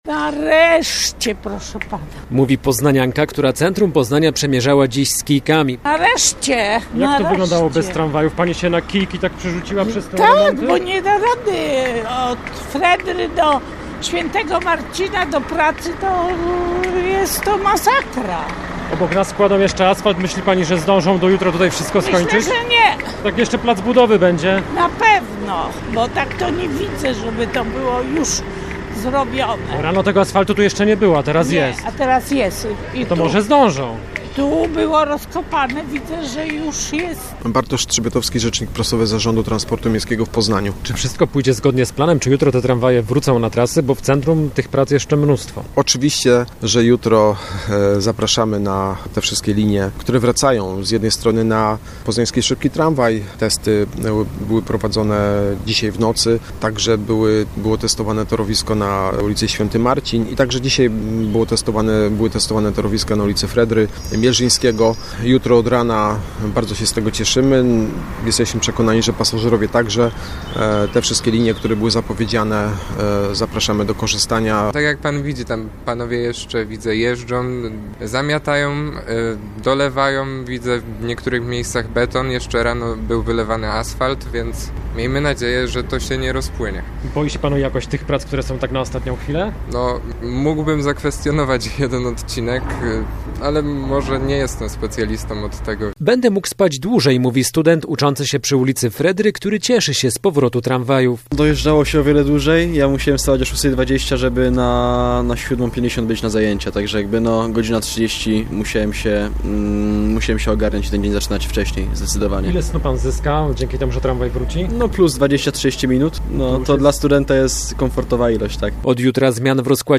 - mówi student, który dziś przyglądał się pracom w centrum Poznania.